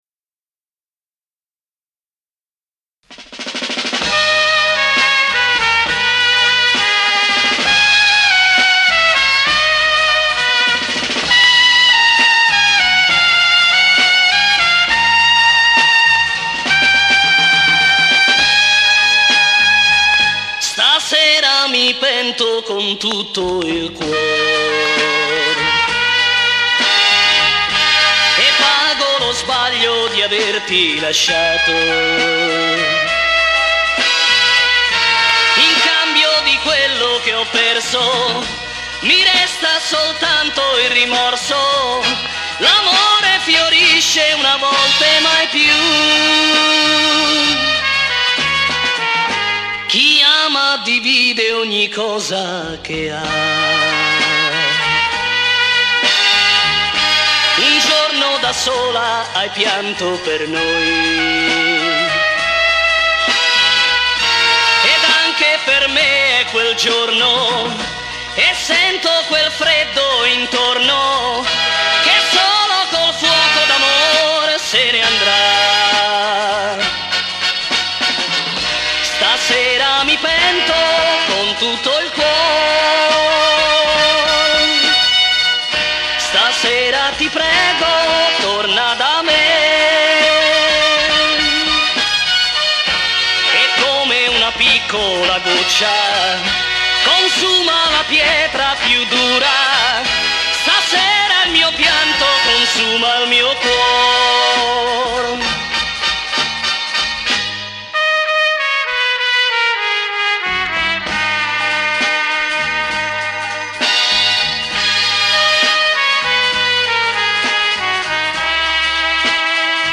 STEREOFONICO compatibile   MADE IN ITALY